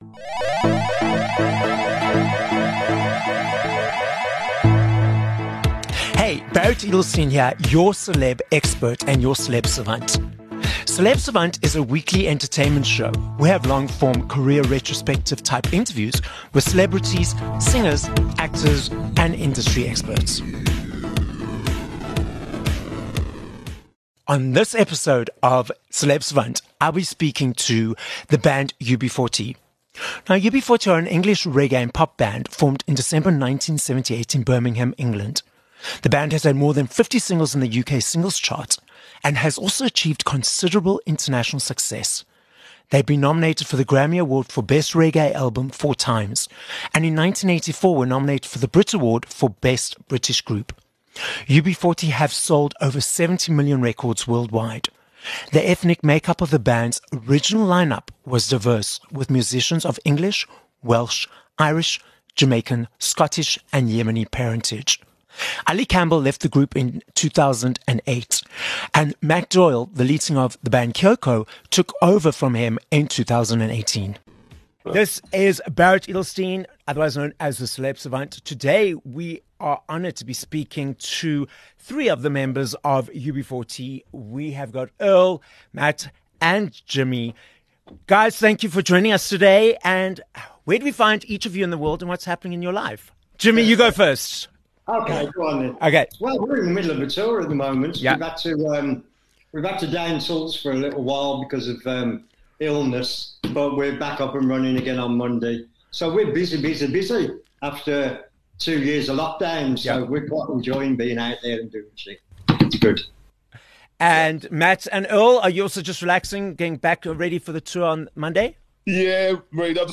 13 Jul Interview with UB40